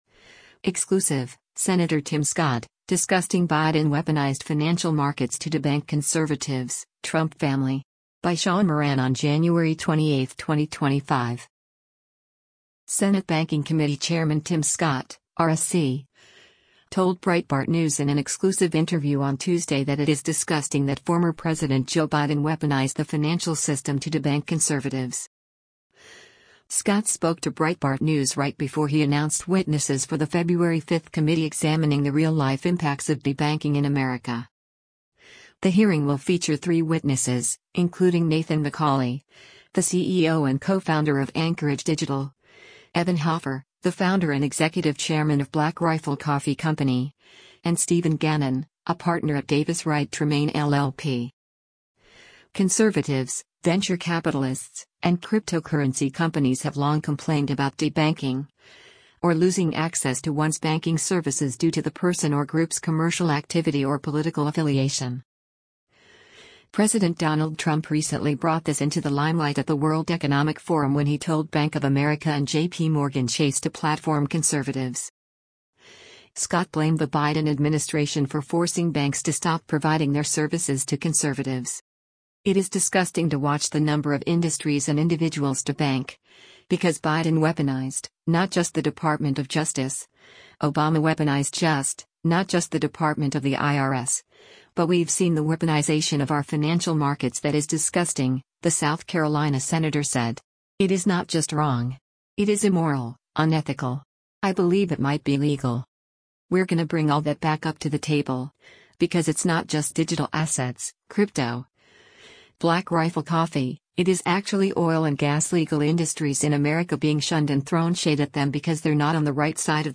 Senate Banking Committee Chairman Tim Scott (R-SC) told Breitbart News in an exclusive interview on Tuesday that it is “disgusting” that former President Joe Biden “weaponized” the financial system to “debank” conservatives.